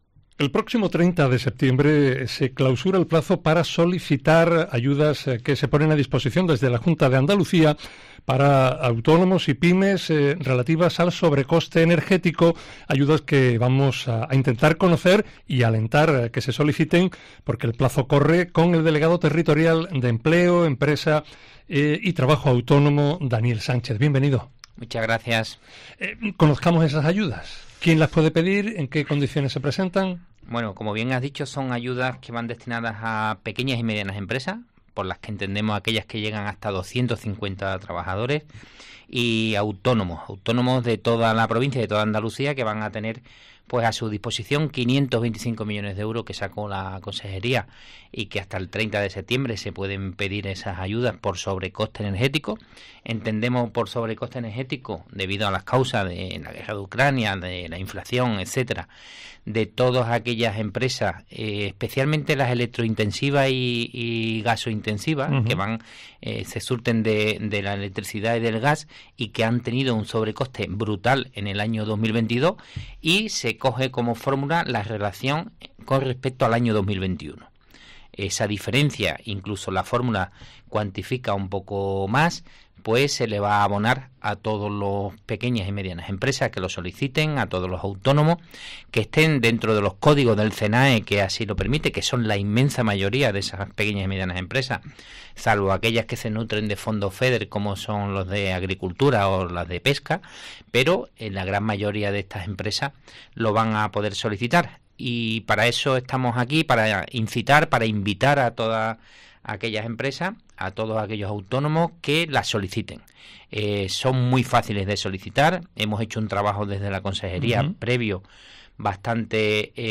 El delegado territorial de Empleo, Empresa y Trabajo Autónomo en Cádiz, Daniel Sánchez, invita a ello desde los estudios de COPE en Jerez